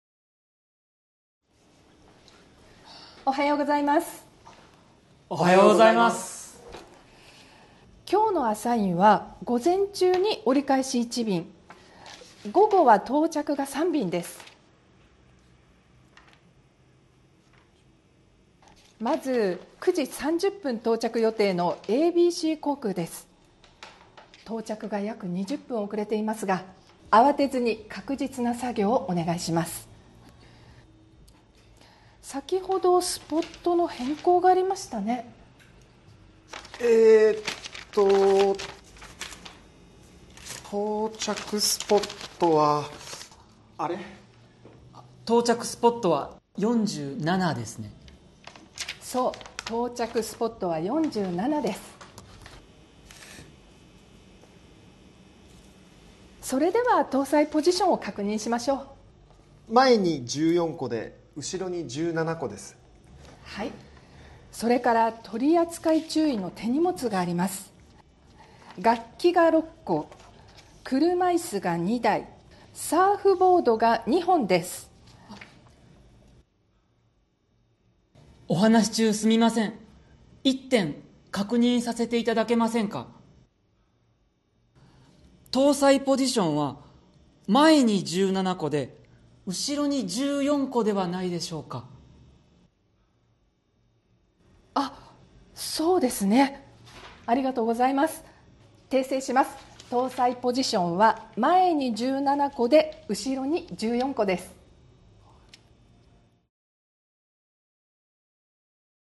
Role-play Setup